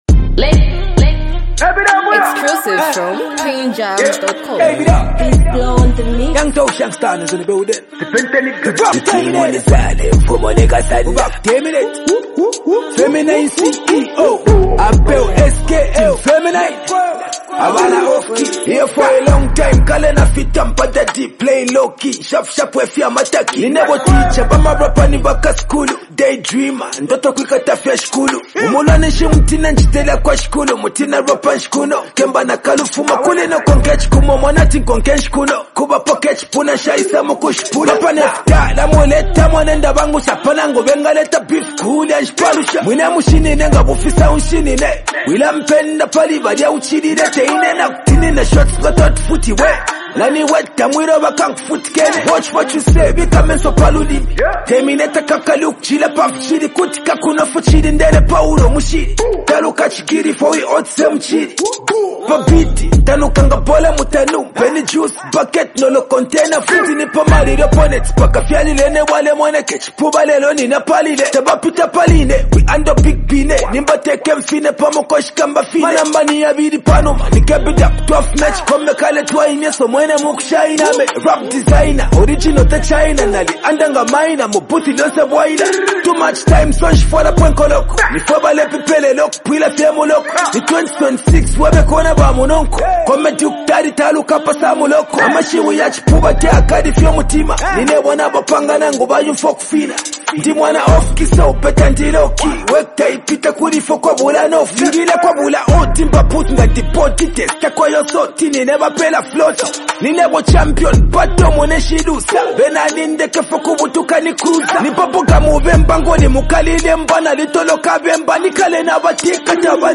Right from the intro, the energy is intense.